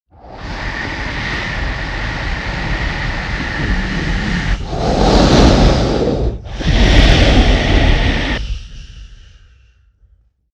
creepy-breath-sound